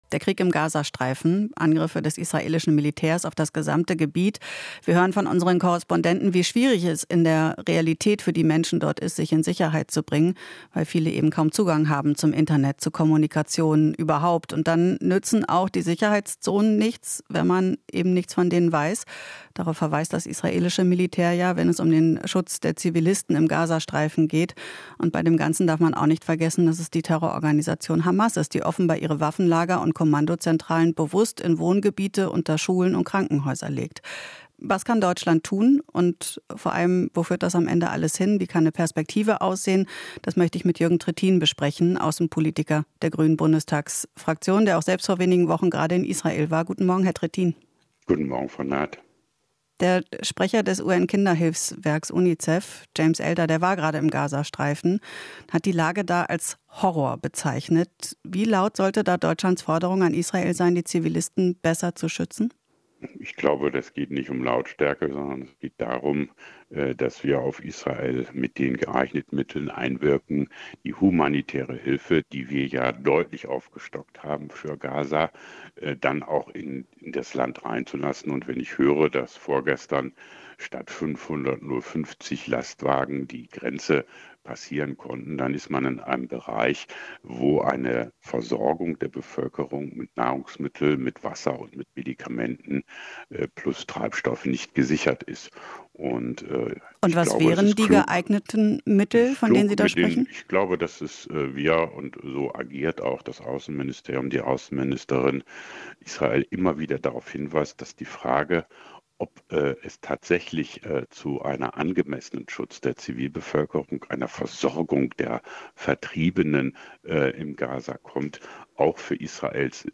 Interview - Trittin: Schutz der Zivilbevölkerung in Gaza ist im Interesse Israels